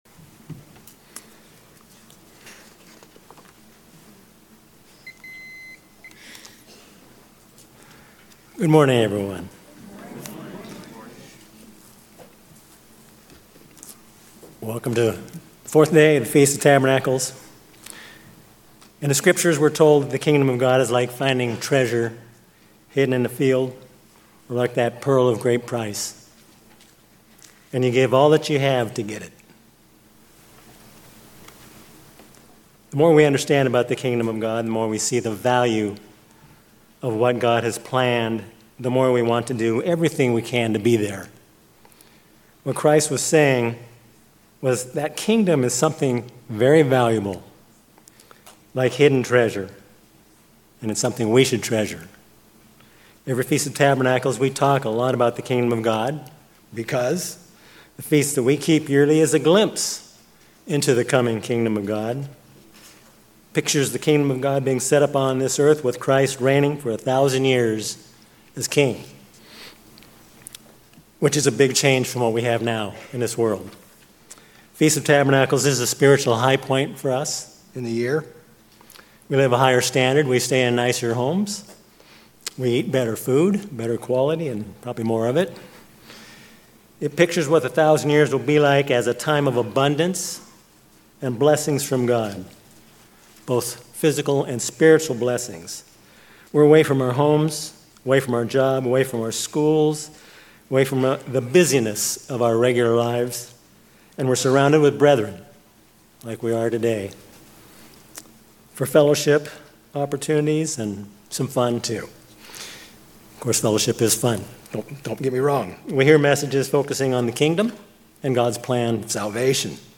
Feast of Tabernacles
Given in Klamath Falls, Oregon